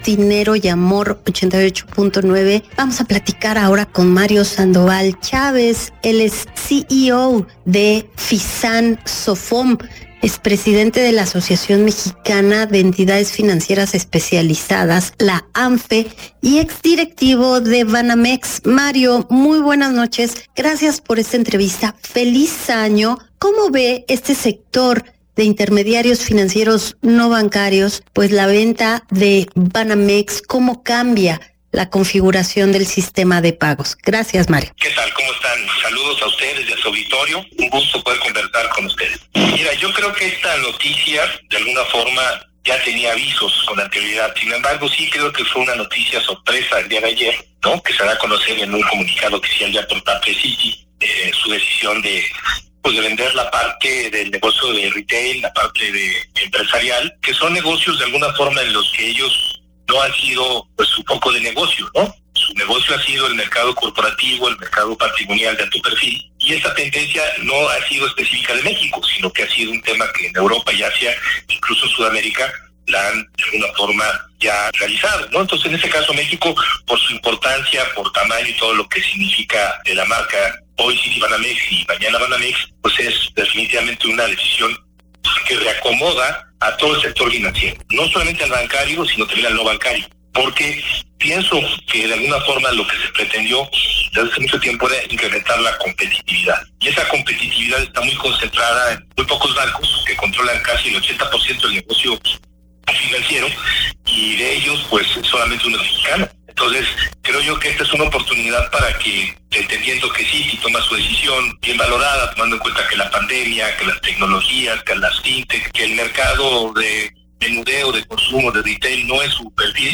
Entrevista en Grupo Acir